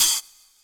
Closed Hats
HATTRAM.wav